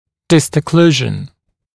[ˌdɪstə’kluːʒ(ə)n][ˌдистэ’клу:ж(э)н]дистальный прикус